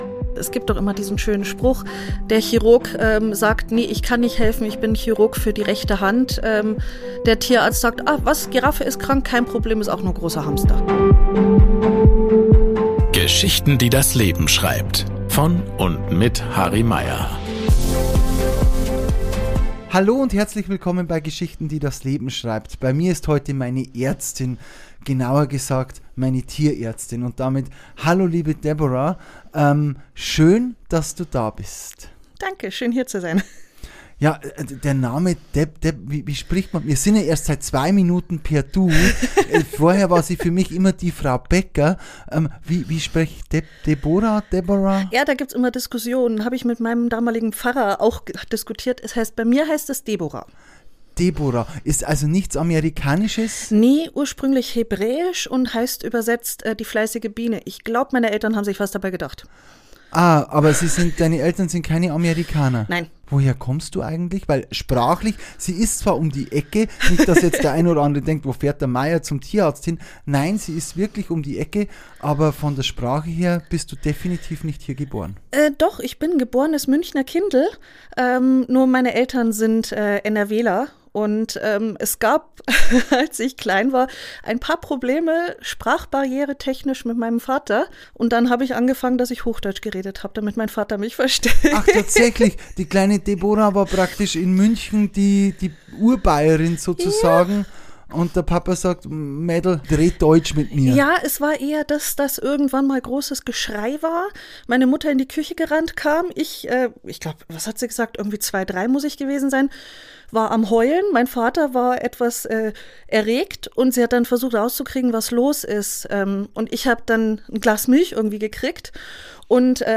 Doch wie wird man eigentlich Tierärztin? Und wie geht man mit den emotionalen Herausforderungen um, wenn Tiere gehen müssen? Ein tiefgründiges Gespräch über Kindheitsträume, schwere Entscheidungen und das Band zwischen Mensch und Tier.